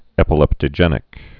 (ĕpə-lĕptə-jĕnĭk)